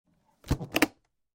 Звуки чемодана
Звук приподнятой крышки старого чемодана